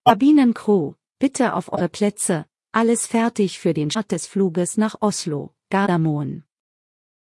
Announcements
CrewSeatsTakeoff.ogg